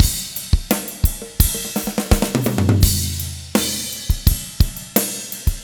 13 rhdrm85roll.wav